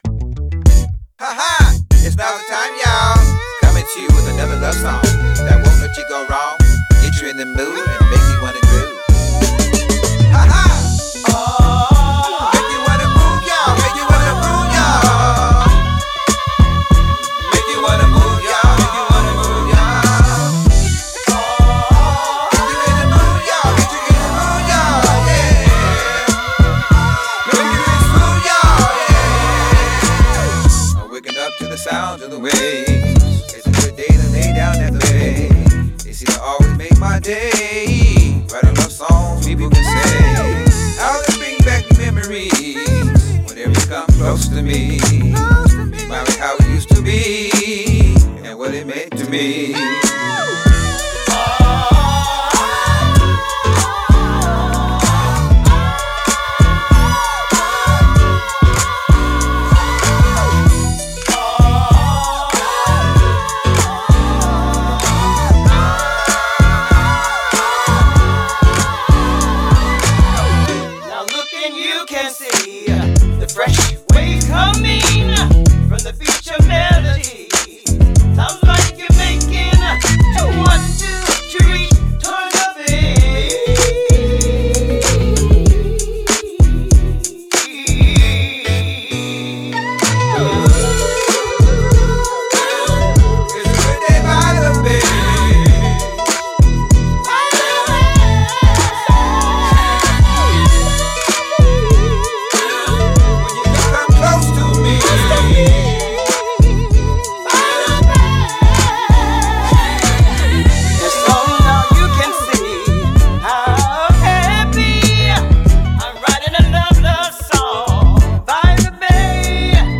Soul
Vocals